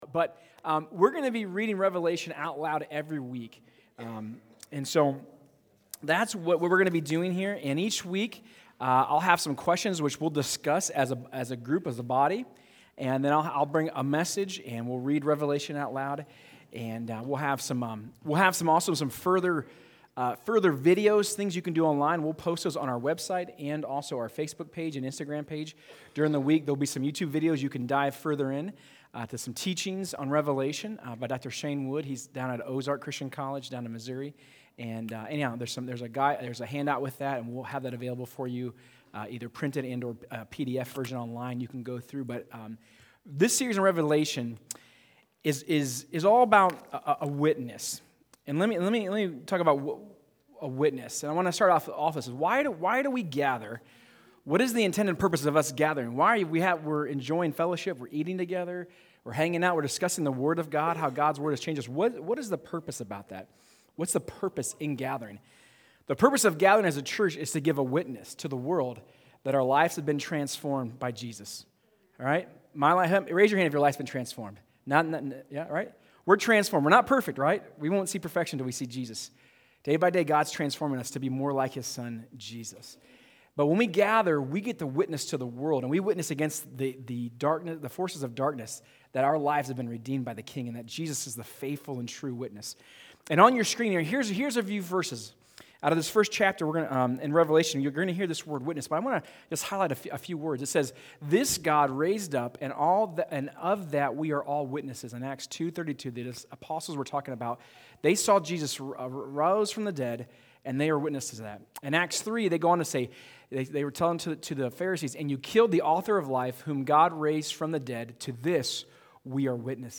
Sermons | Crosspointe Church